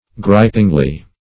gripingly - definition of gripingly - synonyms, pronunciation, spelling from Free Dictionary Search Result for " gripingly" : The Collaborative International Dictionary of English v.0.48: Gripingly \Grip"ing*ly\, adv.
gripingly.mp3